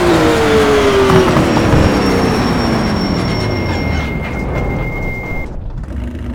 Index of /server/sound/vehicles/lwcars/porsche_911_rsr
slowdown_highspeed.wav